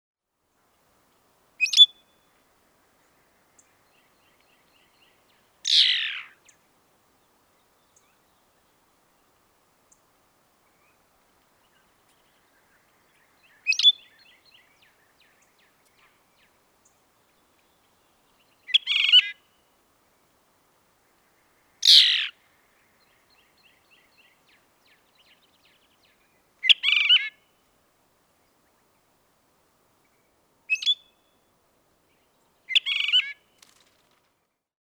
На этой странице собраны записи пения свиристелей – птиц с удивительно нежным и мелодичным голосом.
Звуки свиристелей: Чёрный свиристель (Phainopepla)